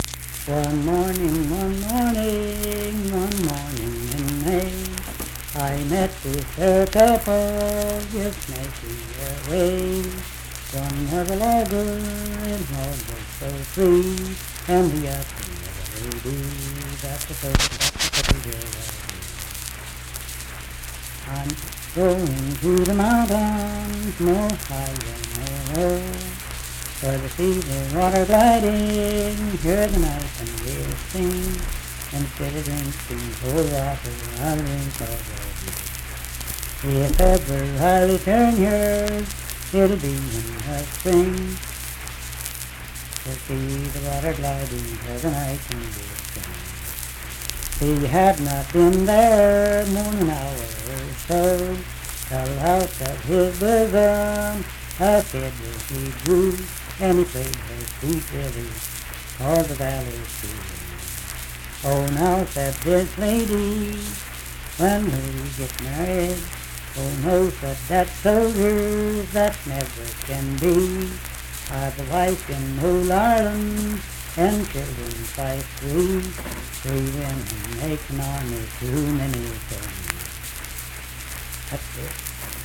Unaccompanied vocal music performance
Verse-refrain 6(4).
Voice (sung)